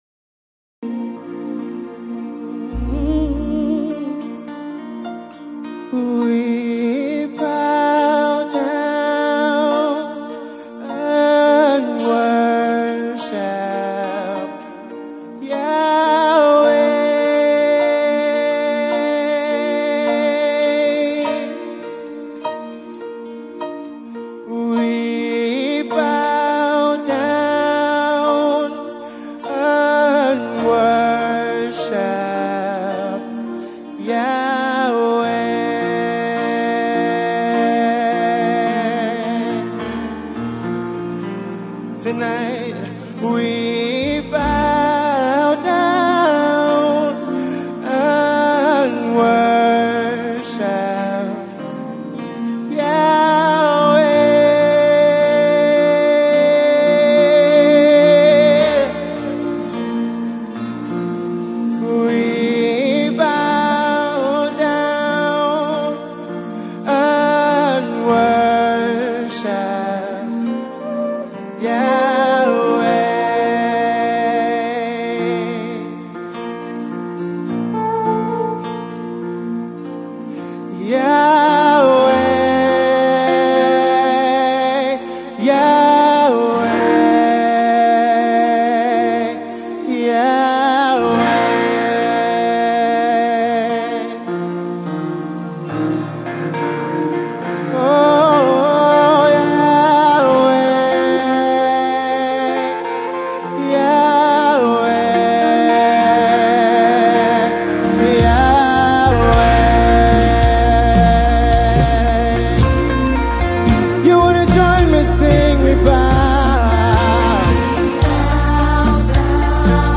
refreshing worship masterpiece